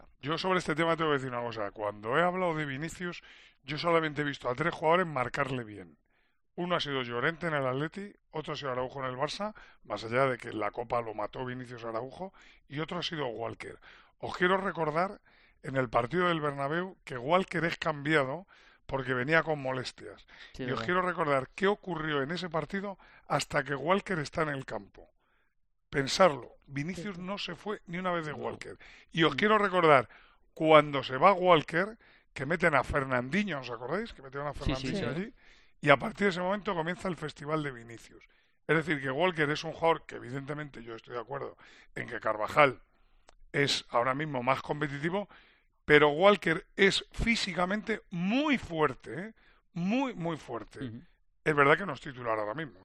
AUDIO: El narrador del encuentro de este martes en el Santiago Bernabéu explica quién puede ser el hombre que utilice Pep Guardiola para marcar al brasileño...